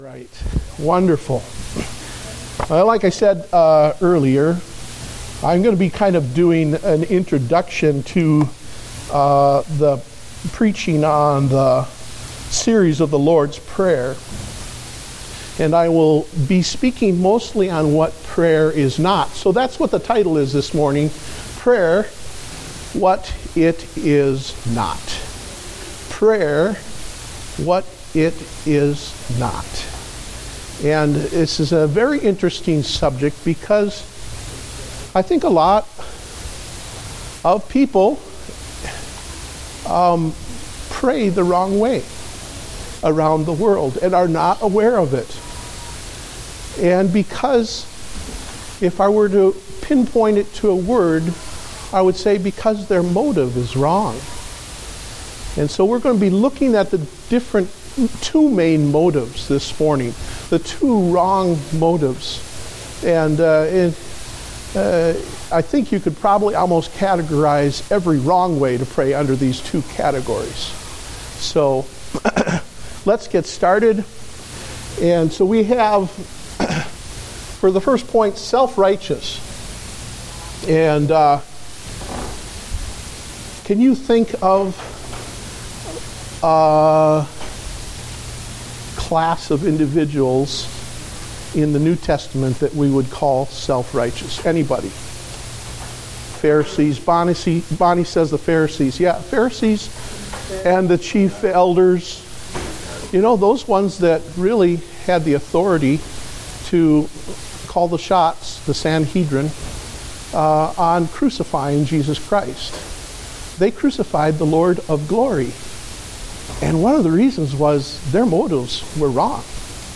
Date: June 28, 2015 (Adult Sunday School)